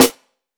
Cardiak Snare 2.wav